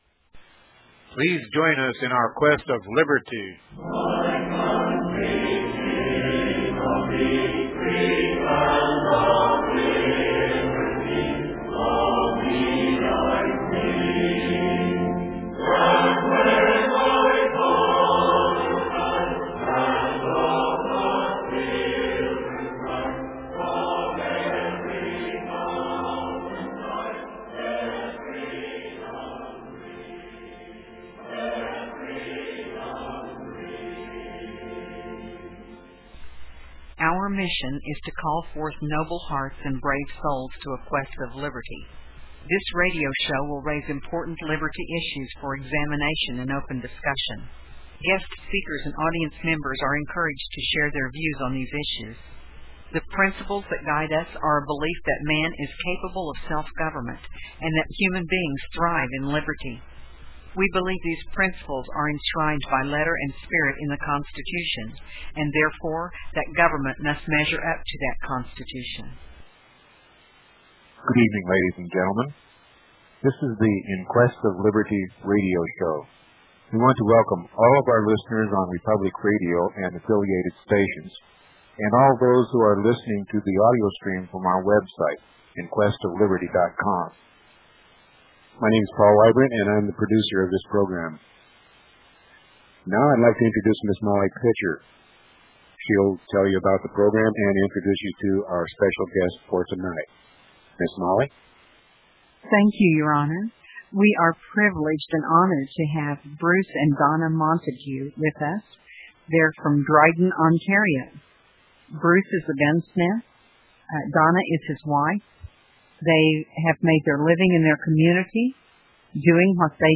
In Quest Of Liberty Radio Interview